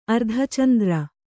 ♪ ardhacandra